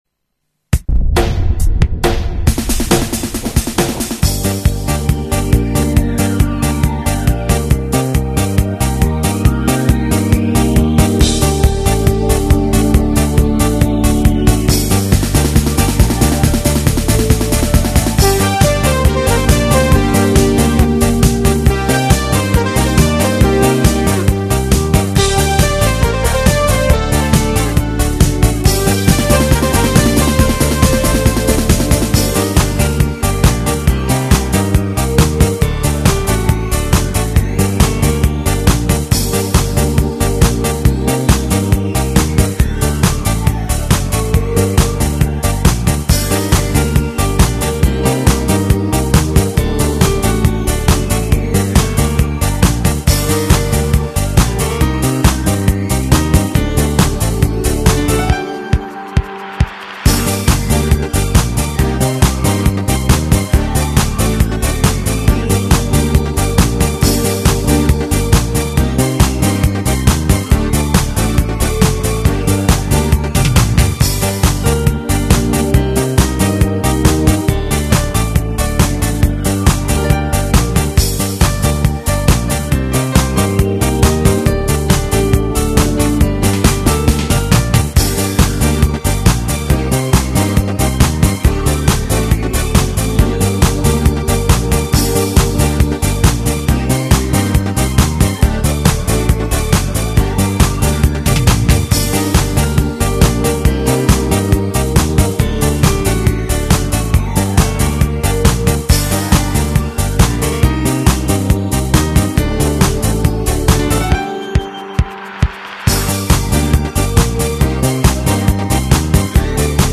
Предлагаем вам музыкальное сопровождение для выполнения упражнений.
Утренняя_гимнастика_средняя_группа.mp3